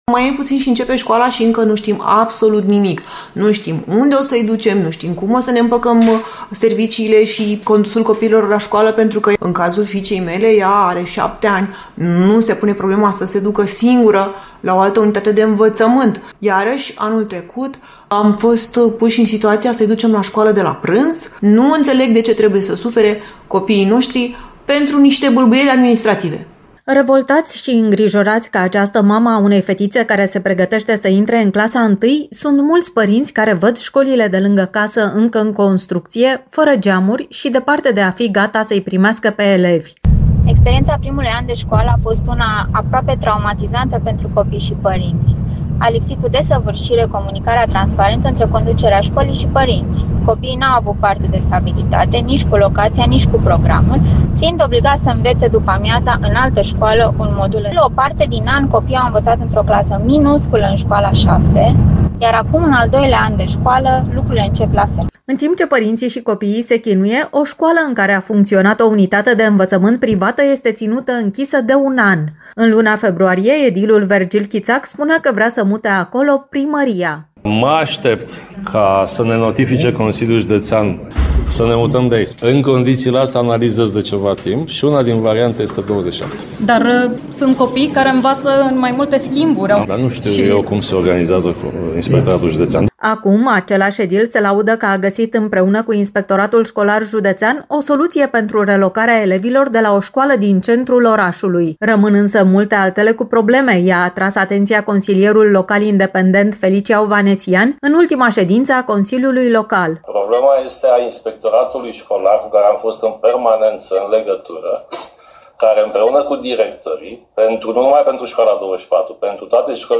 Înțeleg că trebuie renovate școlile, dar nu înțeleg de ce trebuie să sufere copiii noștri pentru niște bâlbâieli administrative, ne-a declarat mama unei fetițe care se pregătește să intre în clasa I la Școala Generală nr. 7 „Remus Opreanu” din Constanța.